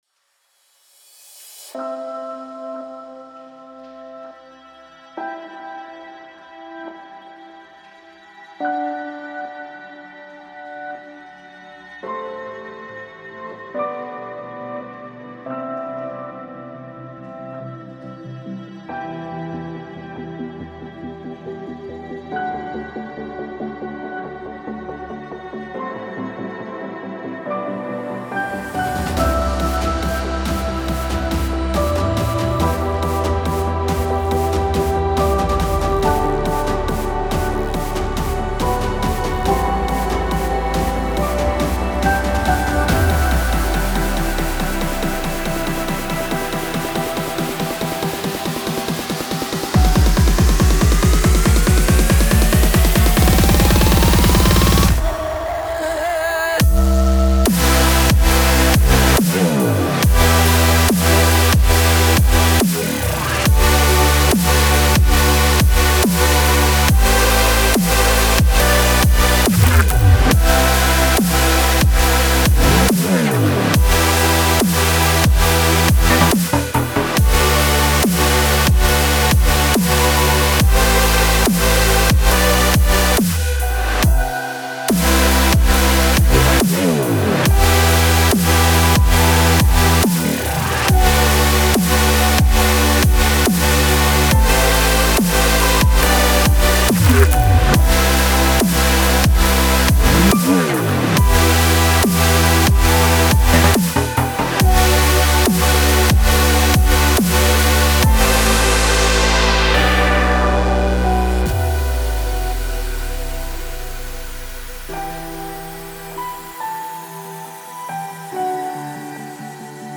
это трек в жанре альтернативного R&B